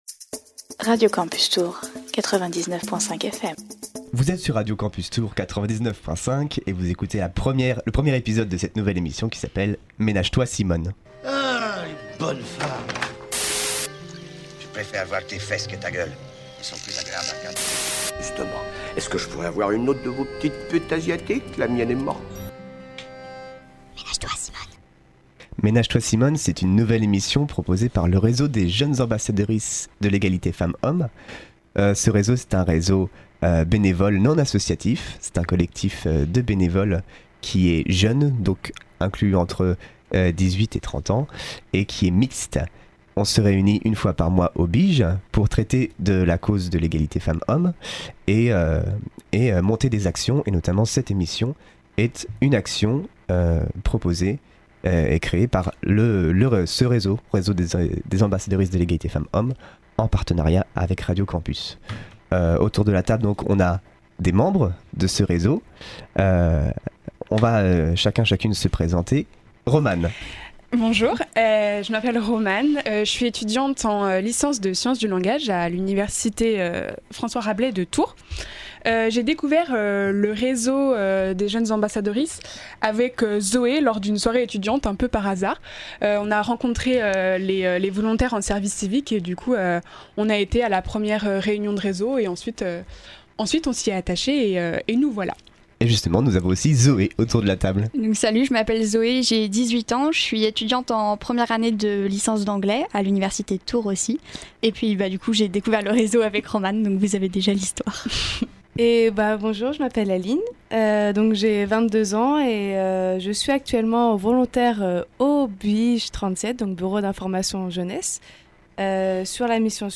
Dans cette toute première émission de Ménage-toi Simone, nos quatre chroniqueur·euse·s ont présenté quelques actualités pour ensuite en débattre, et plus particulièrement sur le sujet suivant : Ada Hegerberg, première footballeuse à gagner le Ballon d’Or féminin. Suite à ce débat, une de nos chroniqueuses aborde le sujet de la femme migrante et de son invisibilité dans les médias.